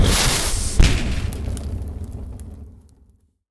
fire-bolt-002-30ft.ogg